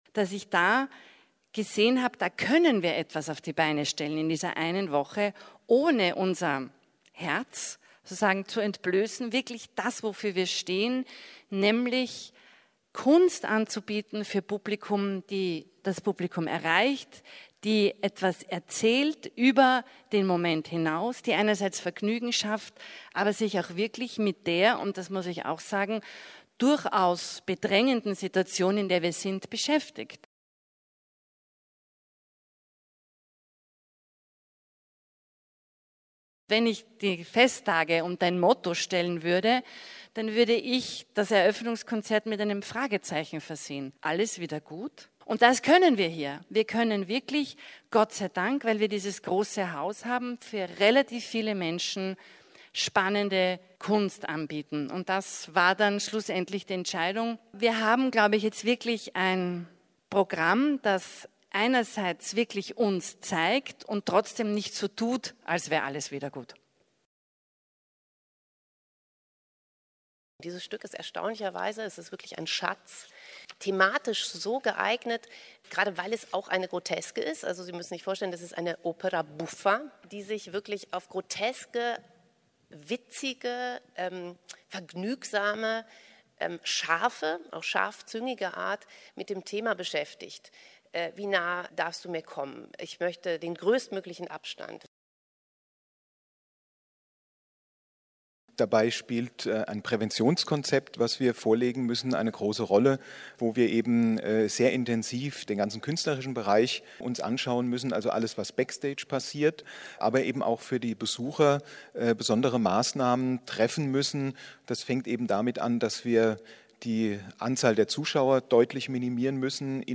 Festtage im Festspielhaus - Feature
bregenz_festtage-festspielhaus-feature.mp3